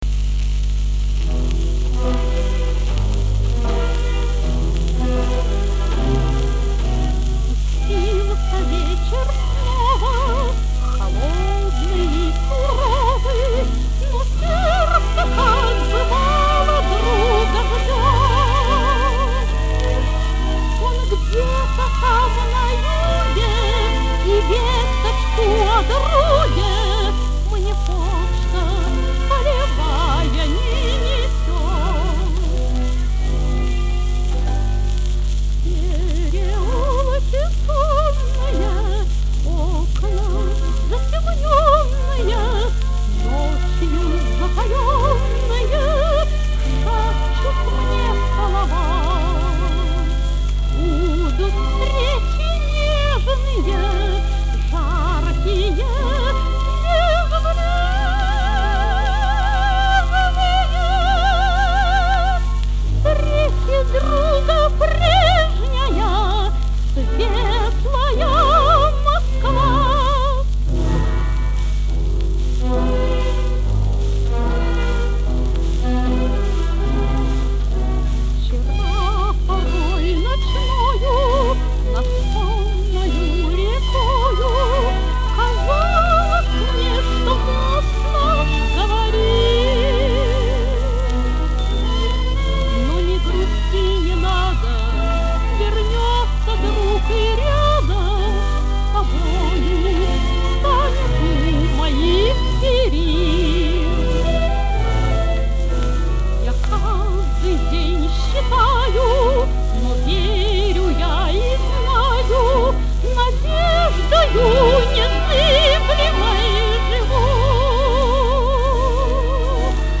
Лирика военных лет.